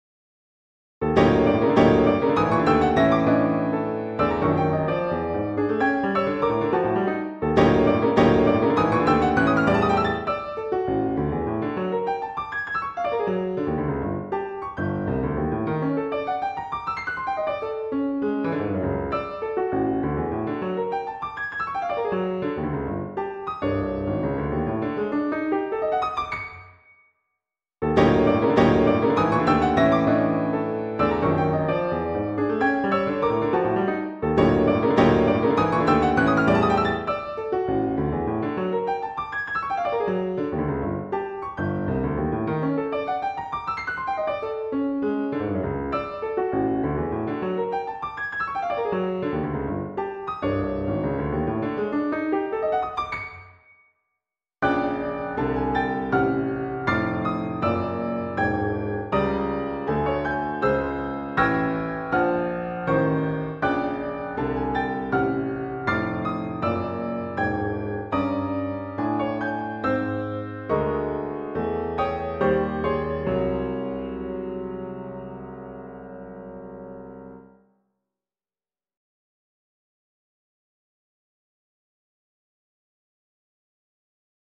Works for automatic piano(1998)  8:27